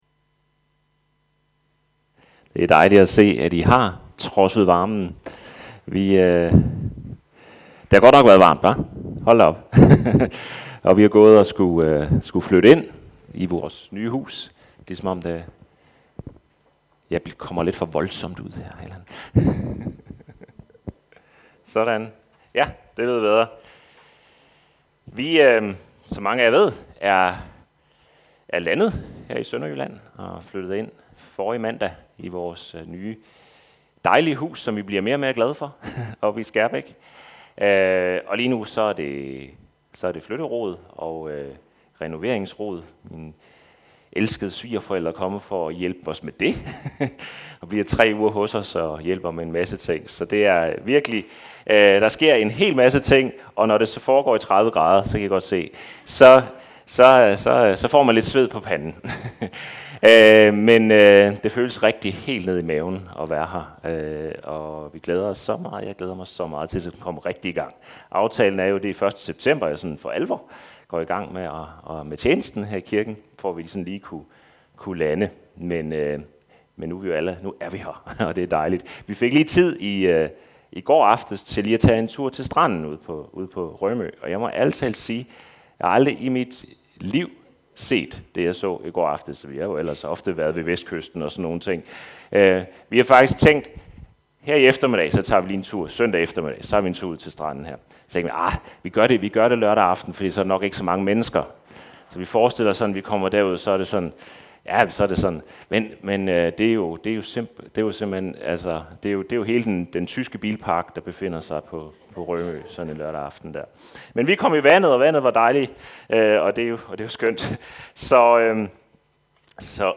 Prædikener fra Tønder Frikirke Service Type: Gudstjeneste « Levende vand til de tørstige Jesus